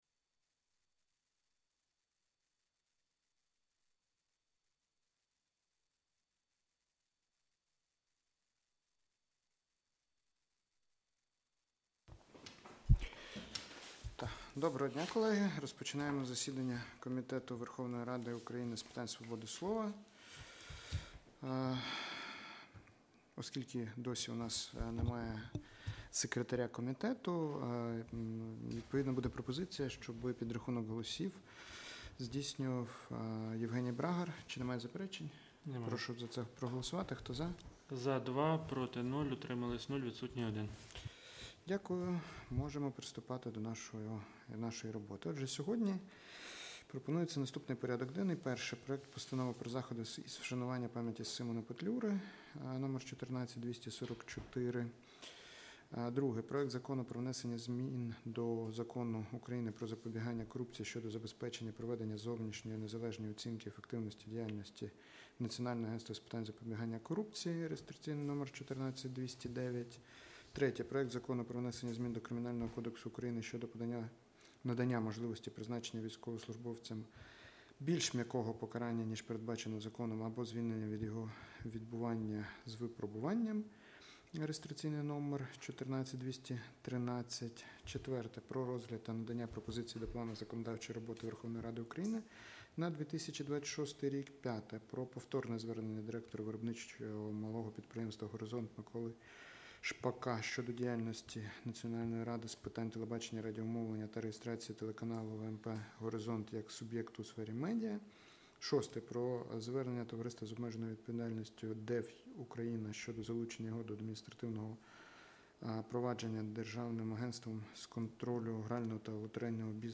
Аудіозапис засідання Комітету від 2 грудня 2025р.